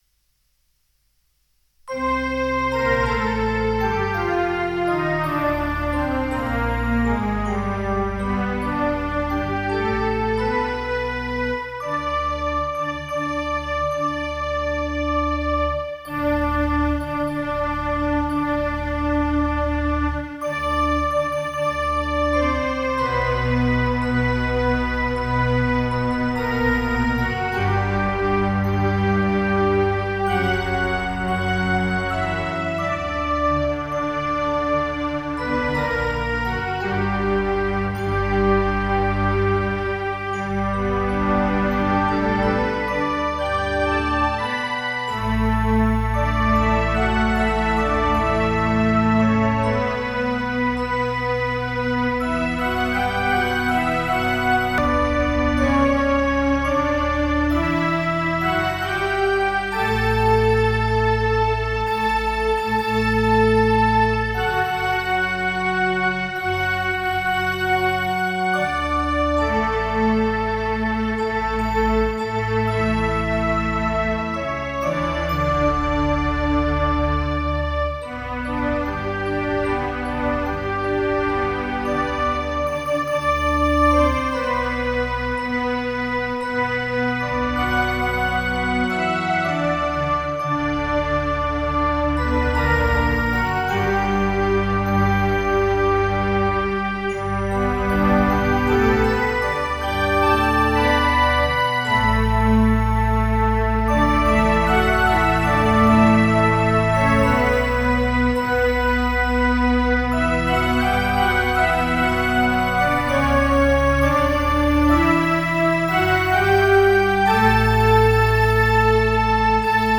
Podłączyłem obydwa instrumenty klawiszowe do miksera dzięki czemu mogą na nim grać równolegle dwie osoby.
Grane tylko na słuch, tak jak to słyszę  :)